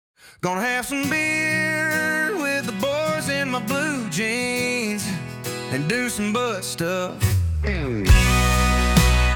country, male vocals